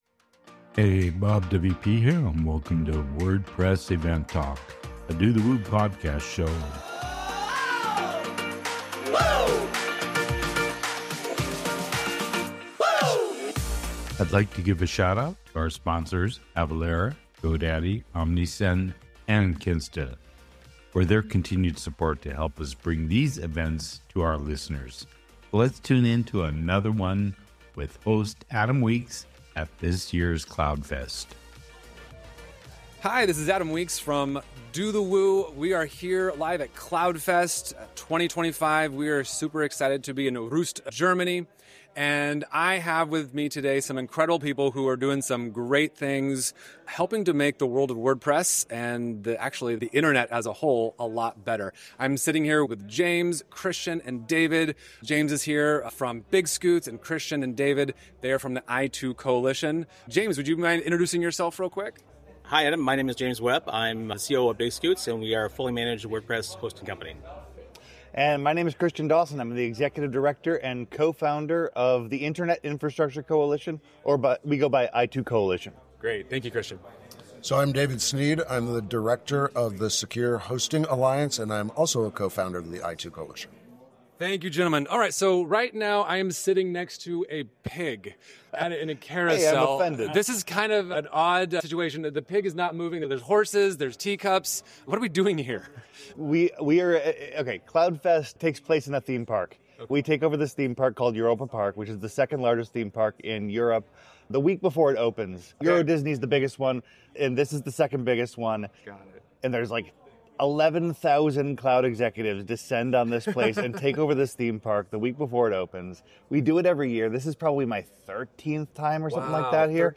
At CloudFest 2025, experts from i2Coalition and BigScoots discuss the importance of collaboration in the hosting industry, addressing legislative challenges while emphasizing WordPress's role in supporting small businesses globally.